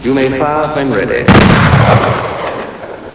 (gunshot.wav)   speaker.gif   Fire.   speaker.gif (31kb)
readfire.wav